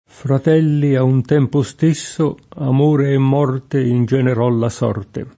morte [m0rte] s. f. — anche con M- maiusc. se personificata: Fratelli, a un tempo stesso, Amore e Morte Ingenerò la sorte [